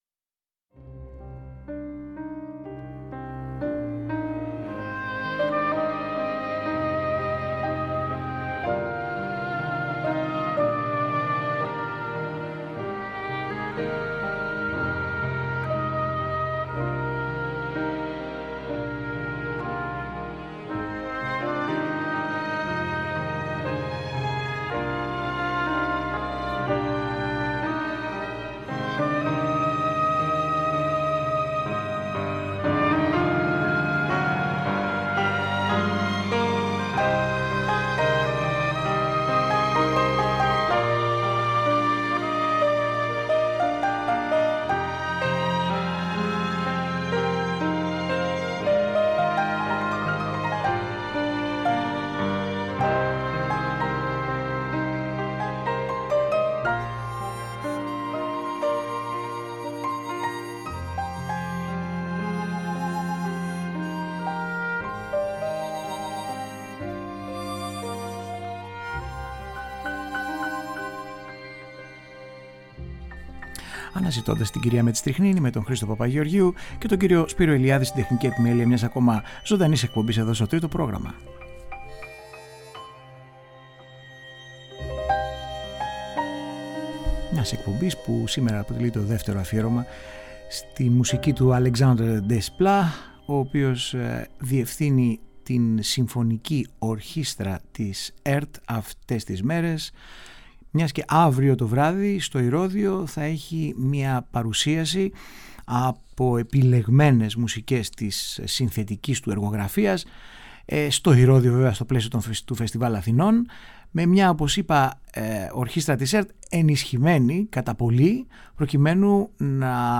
Η μουσική